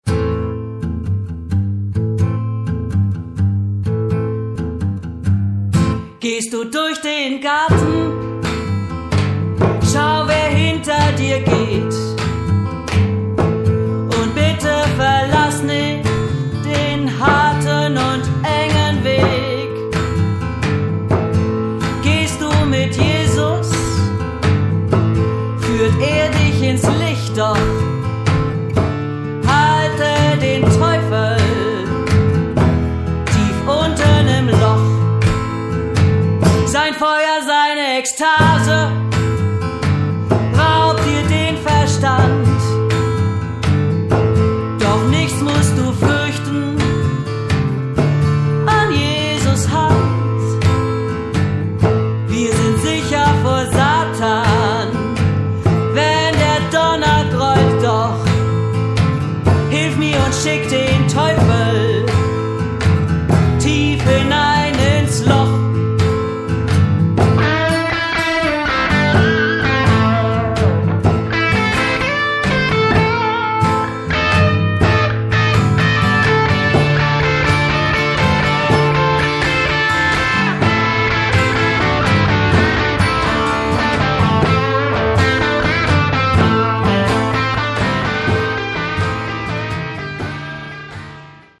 Gesang
A-Gitarren, E-Gitarren, Banjo, Blues-Harp & Percussion